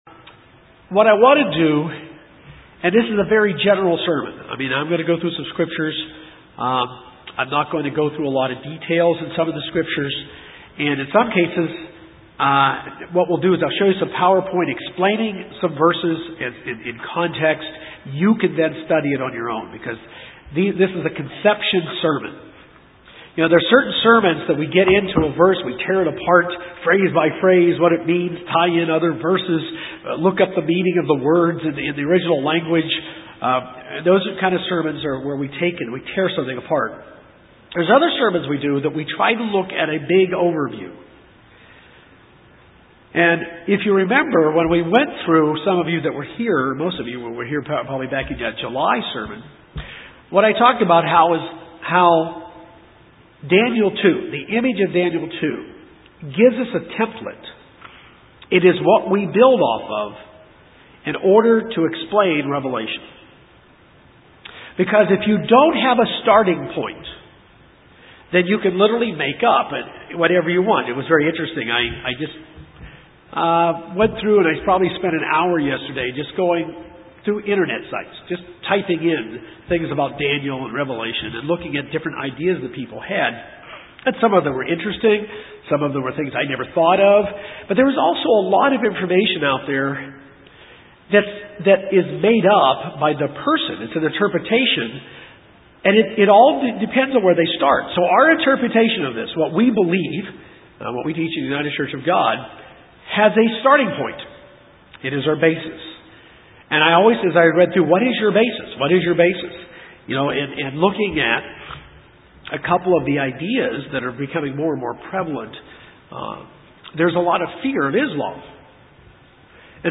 This is the final part in this series of sermons given in July 2014. This sermon looks at Daniel 8.